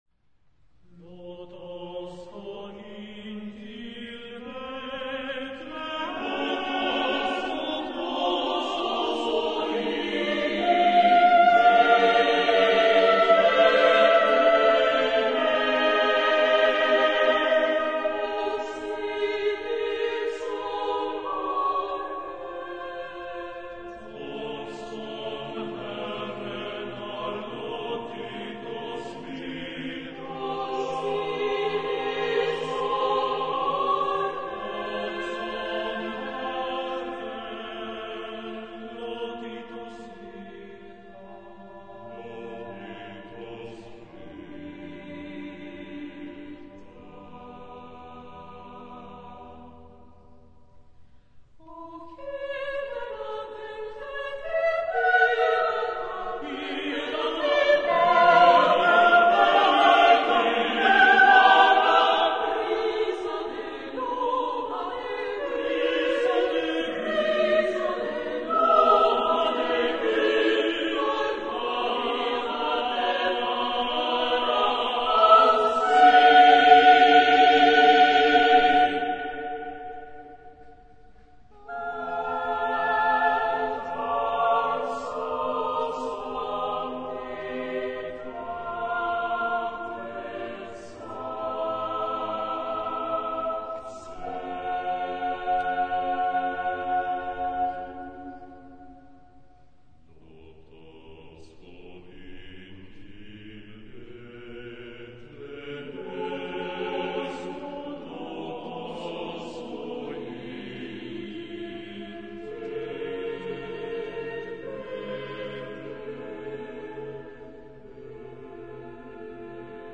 for Choir a c
35 min 15 motets for Church Service